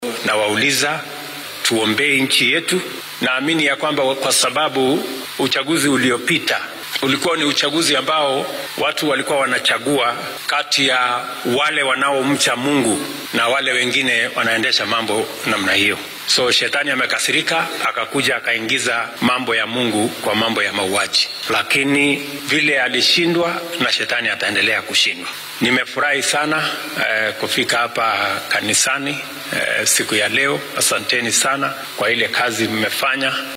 Madaxweynaha dalka William Ruto ayaa hoggaamiyaasha diimeed ka codsaday inay qaranka u duceeyaan si loo gaaro horumarka la hiigsanaya. Baaqan ayuu jeediyay xilli uu maanta ka qayb galay munaasabadda kaniisadda AIC ee Milimani oo ka tirsan Nairobi. Waxaa uu hoggaamiyaha dalka dhanka kale cambaareeyay in qaar ka mid ah baadariyaasha ay halis geliyeen nolosha dad ku xiran iyagoo ka dhaadhiciyay waxyaabo aan sax ahayn sida inay cuntada ka af xirtaan.